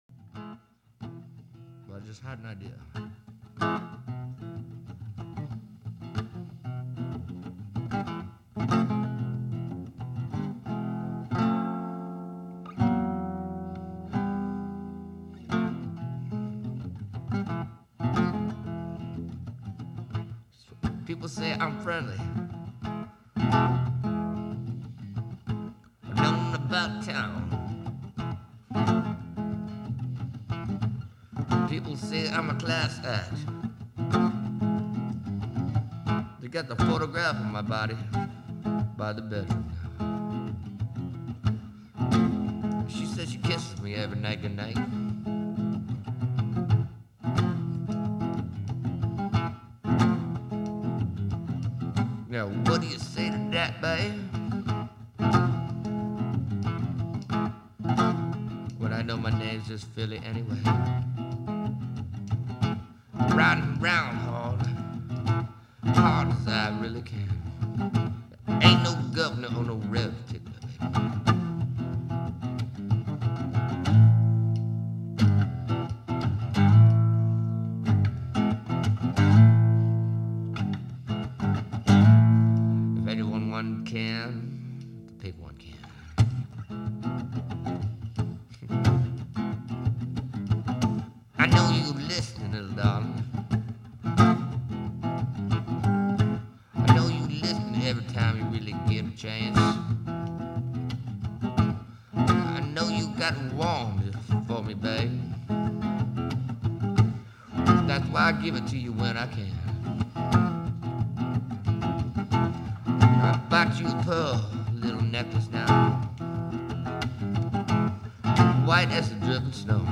Blues Rock type tune